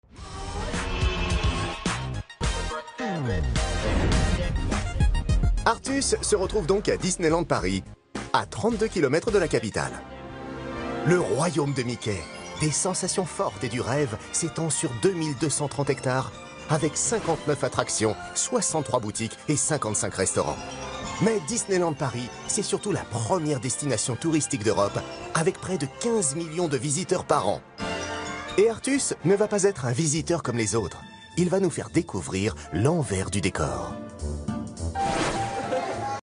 Voix sympa et dynamique sur Disneyland Paris avec Artus.
L’émission avait une thématique d’humour, ce qui m’a permis d’utiliser un ton de voix dynamique, sympa, naturel et souriant.
Ma voix médium s’est parfaitement adaptée à ce projet. Elle a permis de créer une ambiance chaleureuse et conviviale, tout en mettant en valeur les différentes attractions de Disneyland Paris.